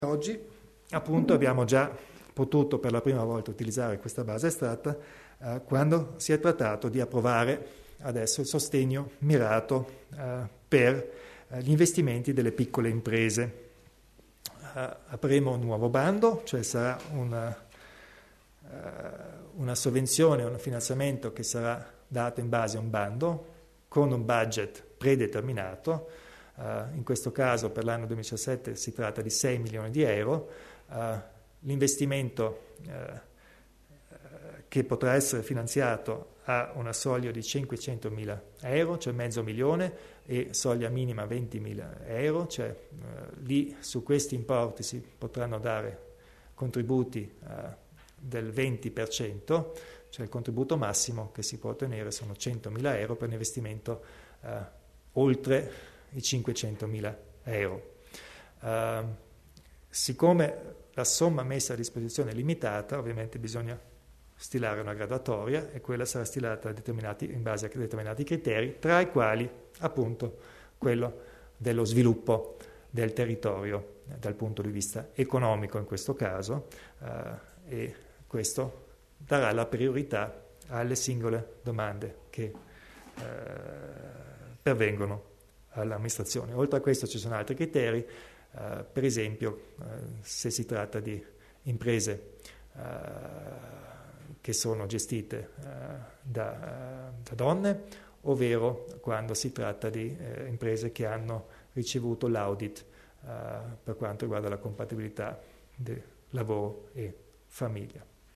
Il Presidente Kompatscher elenca i criteri di sostegno per le piccole imprese